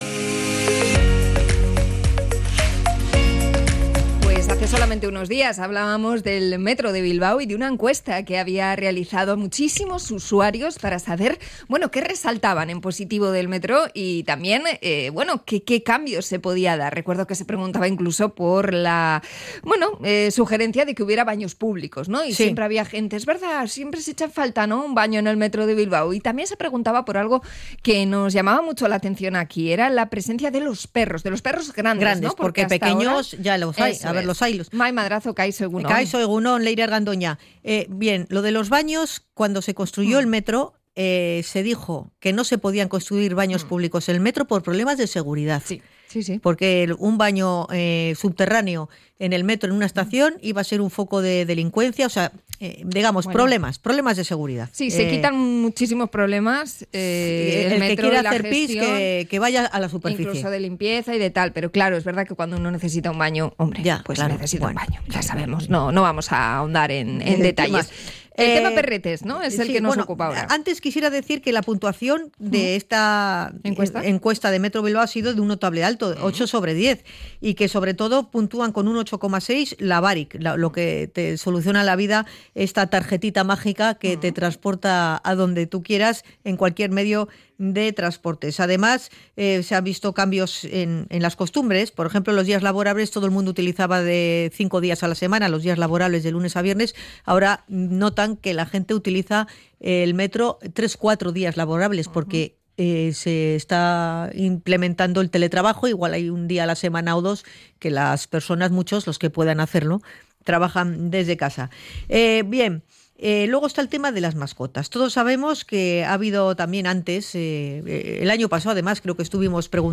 Hablamos con usuarios de Metro Bilbao sobre la posibilidad de compartir este espacio con perros grandes